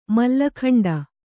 ♪ malla khaṇḍa